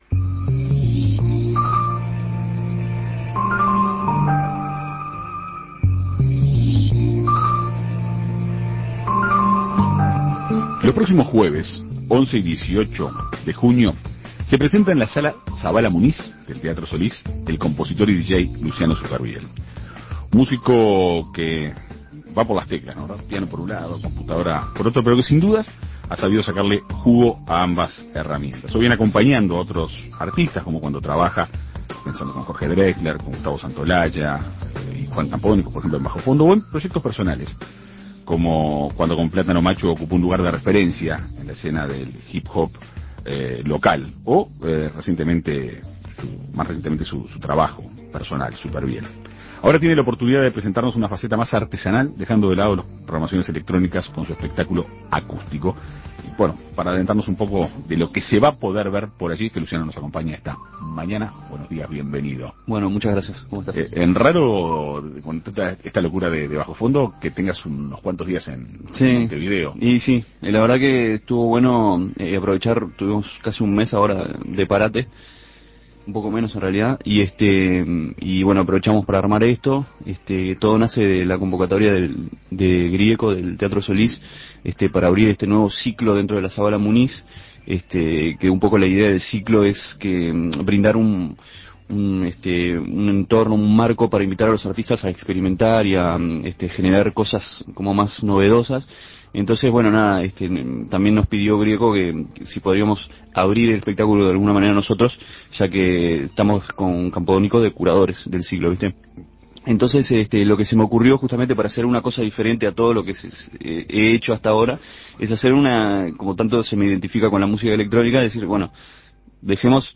Ahora tiene la oportunidad de presentar su faceta más artesanal, dejando de lado las programaciones electrónicas, con su espectáculo "Supervielle acústico". En Perspectiva Segunda Mañana dialogó con el artista para conocer los detalles del espectáculo.